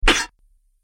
دانلود آهنگ دعوا 36 از افکت صوتی انسان و موجودات زنده
جلوه های صوتی
دانلود صدای دعوا 36 از ساعد نیوز با لینک مستقیم و کیفیت بالا